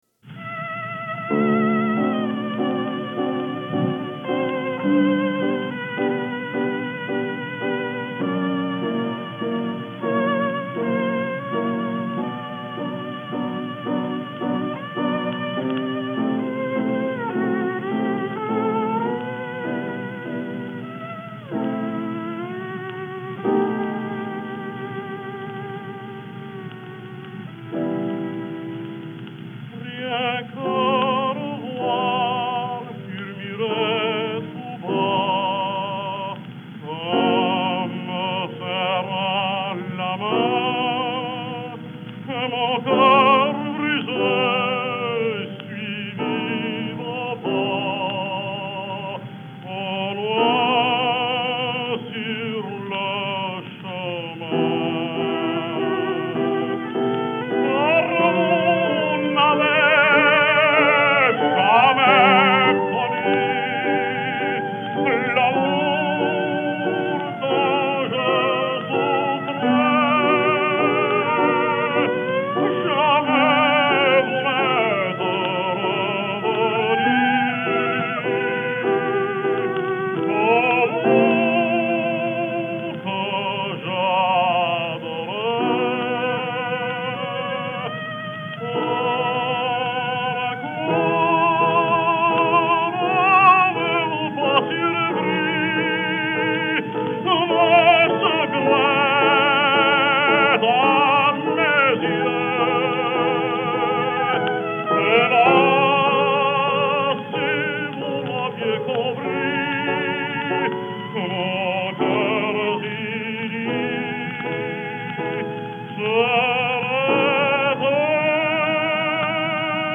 Жанр: Vocal
violin
piano